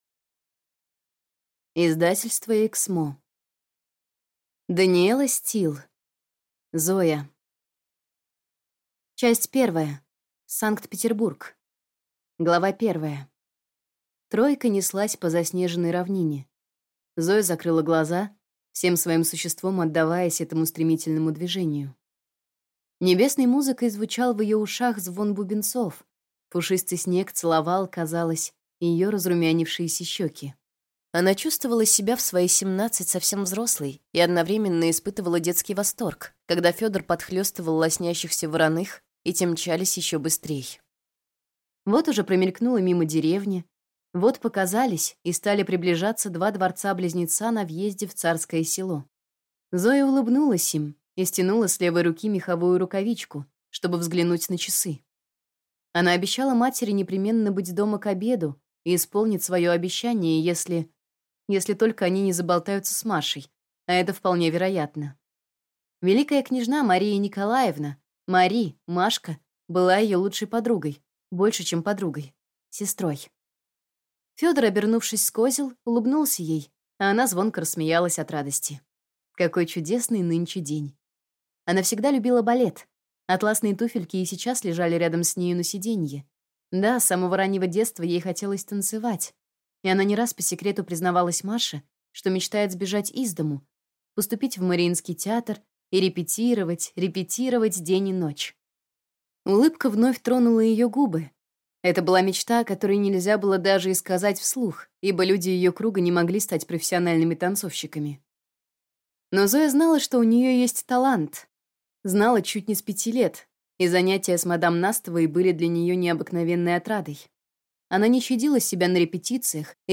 Аудиокнига Зоя | Библиотека аудиокниг